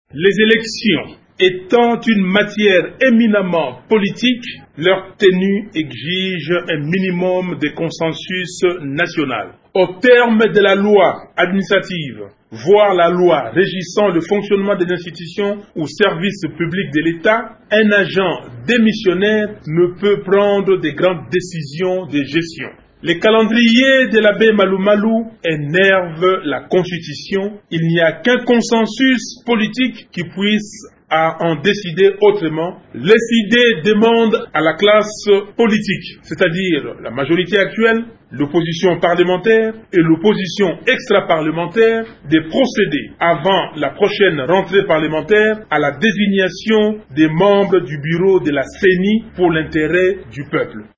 Martin Fayulu a déclaré:
martin-fayulu.mp3